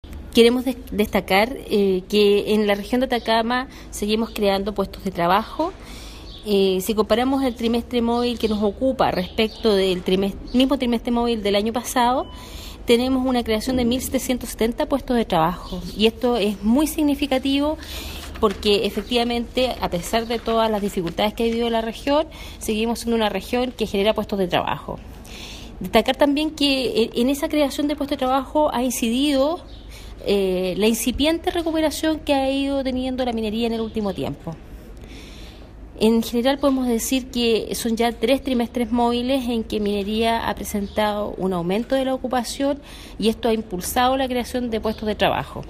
Para Dissa Castellani, Seremi del Trabajo, “esto es muy significativo porque a pesar de todas las dificultades que hemos vivido seguimos siendo una región que genera puestos de trabajo. Destacar también que en estos nuevos puestos de trabajo ha incidido la incipiente recuperación del sector minero en el último tiempo donde ya son tres los trimestres móviles consecutivos donde la minería ha presentado un aumento en la ocupación”.
Seremi-Trabajo-1.mp3